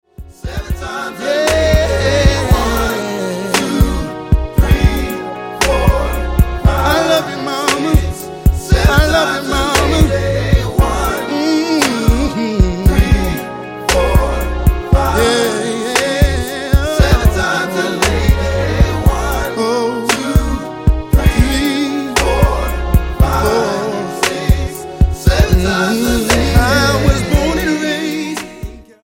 STYLE: R&B
The opening ballad